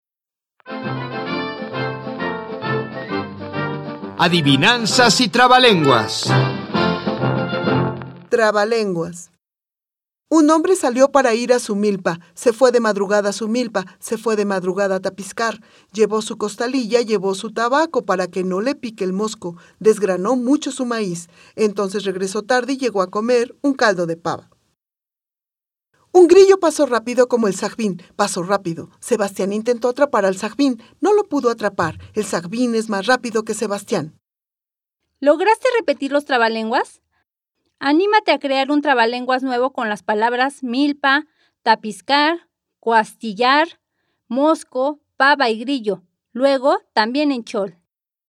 Audio 26. Trabalenguas
133_Trabalenguas.mp3